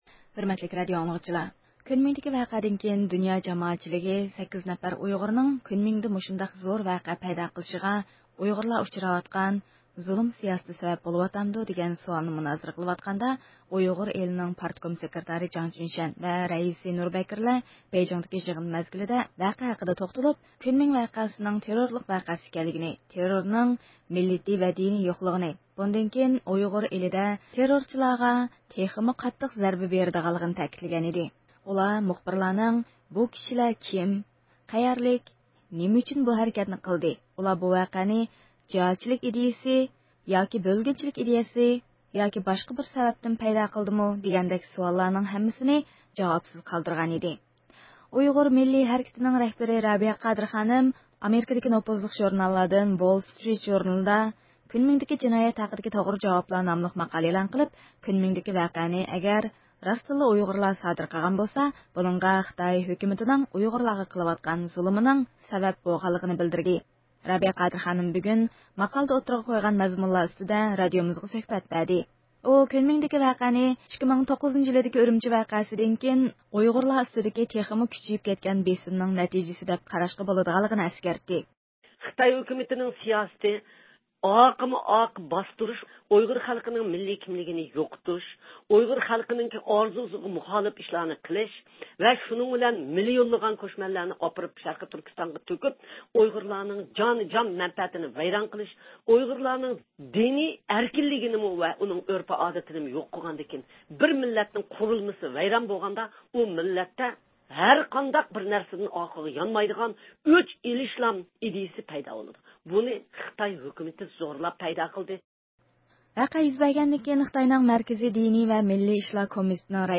رابىيە قادىر خانىم ماقالىدە ئوتتۇرىغا قويغان مەزمۇنلار ئۈستىدە رادىئومىزدا سۆھبەتكە قاتنىشىپ، كۇنمىڭدىكى ۋەقەنى 2009-يىلى ئۈرۈمچى ۋەقەسىدىن كېيىن ئۇيغۇرلار دۇچار بولغان بىر قاتار زۇلۇملارنىڭ نەتىجىسى، دەپ قاراشقا بولىدىغانلىقىنى ئەسكەرتتى.